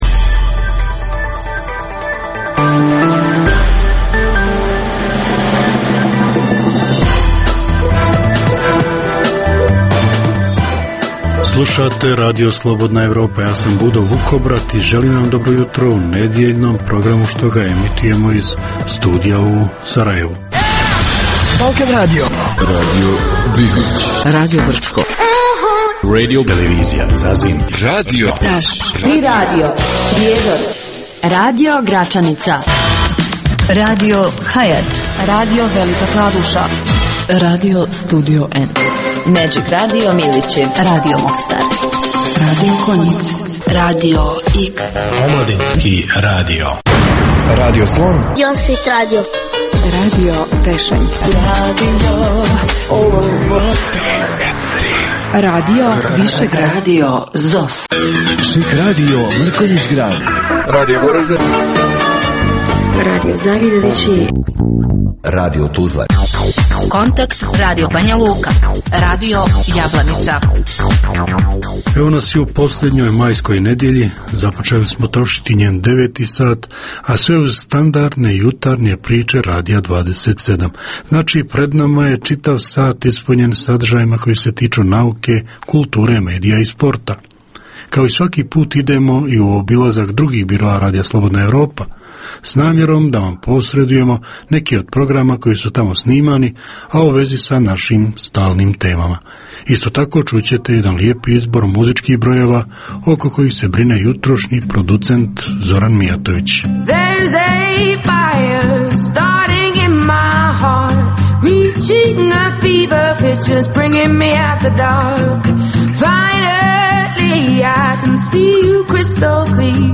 Jutarnji program namijenjen slušaocima u Bosni i Hercegovini.